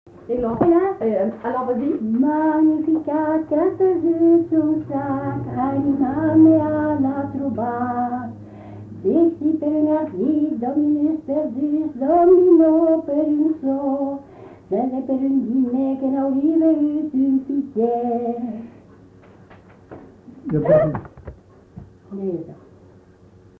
Lieu : Mézin
Genre : conte-légende-récit
Effectif : 1
Type de voix : voix de femme
Production du son : chanté
Classification : parodie du sacré